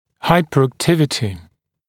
[ˌhaɪpəræk’tɪvətɪ][ˌхайпэрэк’тивэти]гиперактивность, повышенная активность, гиперфункция